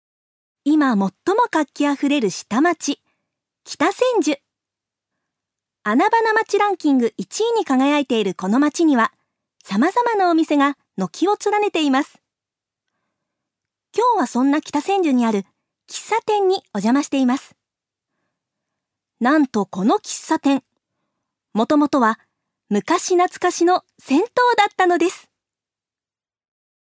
女性タレント
音声サンプル
ナレーション１